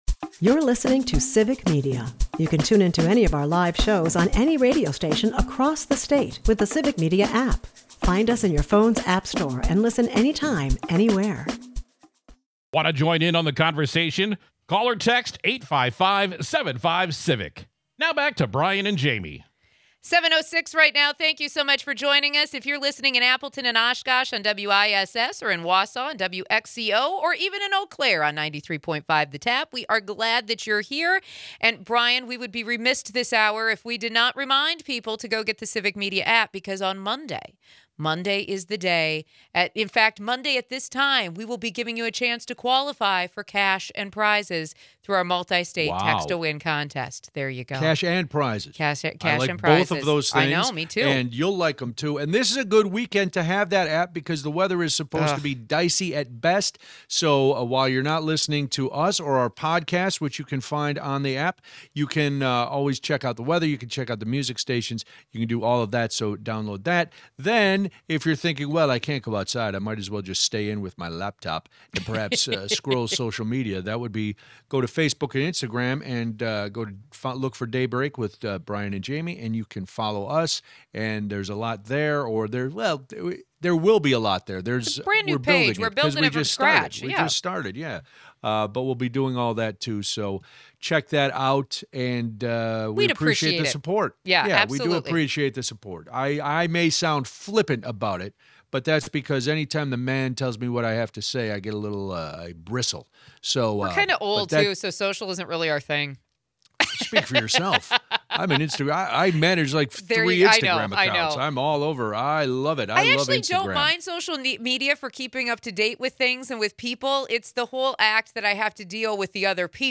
We start the hour talking about the economic impact of tariffs, this conversation comes from news that the White House is taking steps to try to make a permanent fix for illegal tariffs. We also talk about the filibuster and what Senator Ron Johnson had to say about it. At the end of the hour we talk to Democrat candidate for governor and former Lt. Gov. Mandela Barnes about his energy plan.